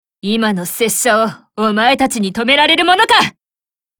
File:Cv-30311 warcry 8.mp3 - 萌娘共享